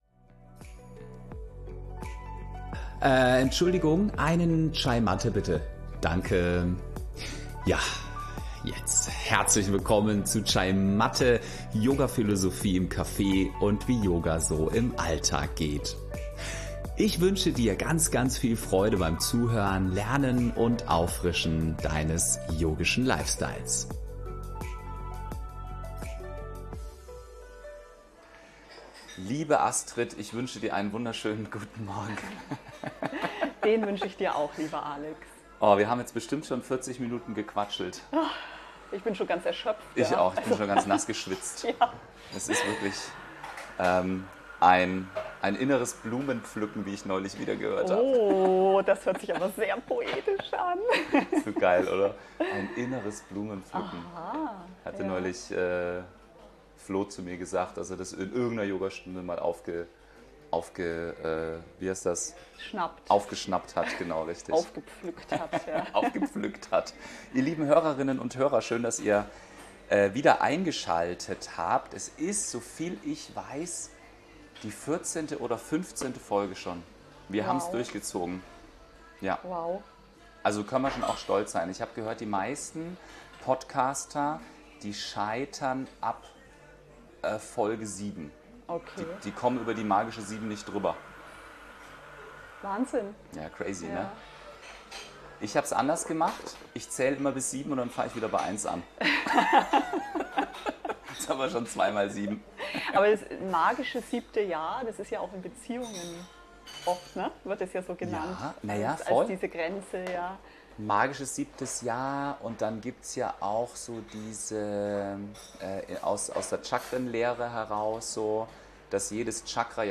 Mal dabei - ein Gespräch über Zweifel, Trägheit, Begeisterung und Zielstrebigkeit